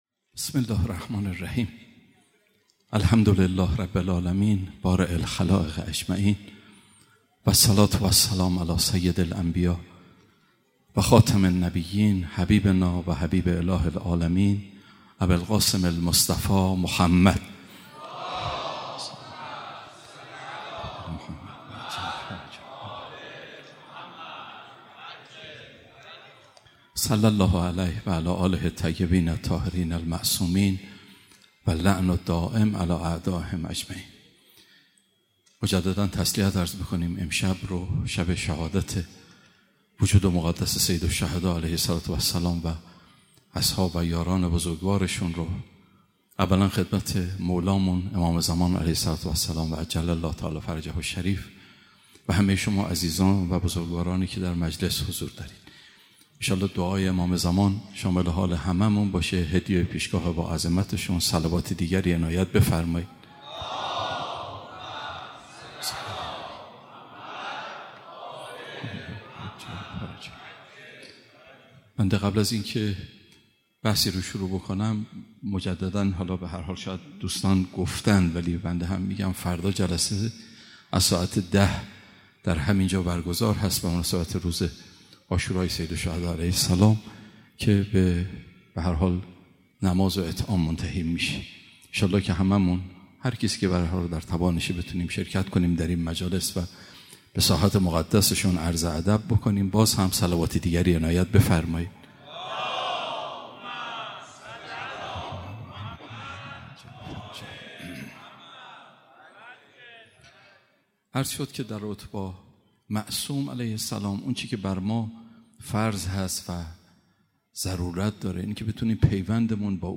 مراسم عزاداری شب دهم محرم هیأت آئین حسینی